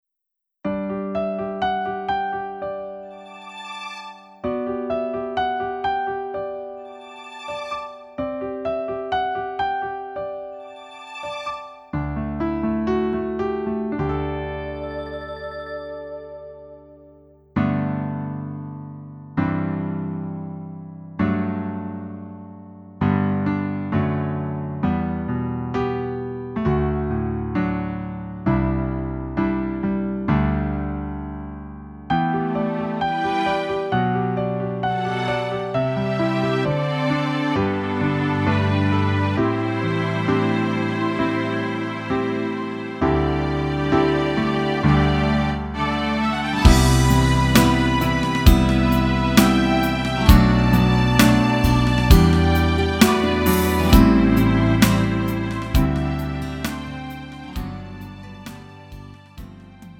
음정 -1키 3:41
장르 가요 구분 Lite MR
Lite MR은 저렴한 가격에 간단한 연습이나 취미용으로 활용할 수 있는 가벼운 반주입니다.